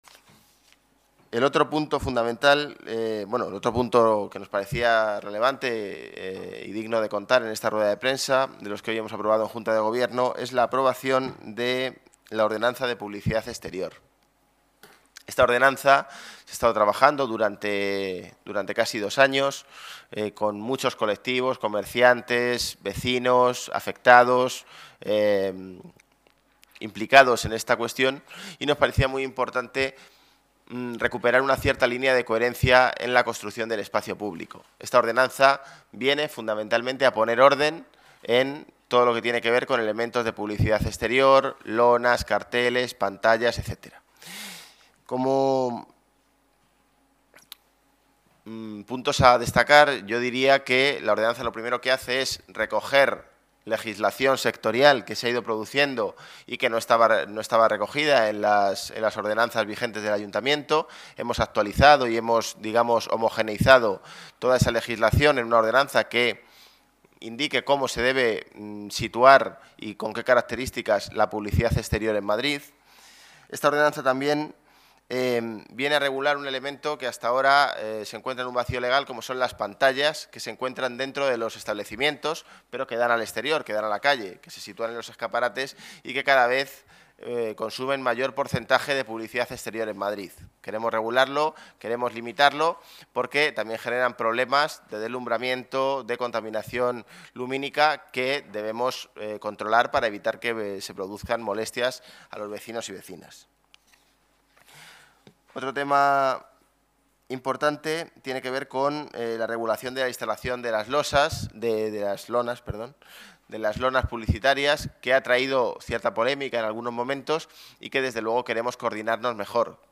Nueva ventana:José Manuel Calvo habla sobre la Ordenanza de Publicidad Exterior